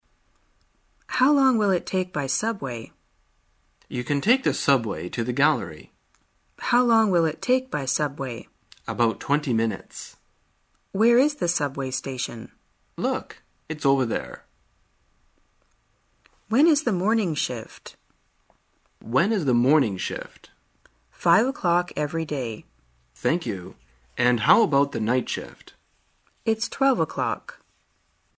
真人发音配字幕帮助英语爱好者们练习听力并进行口语跟读。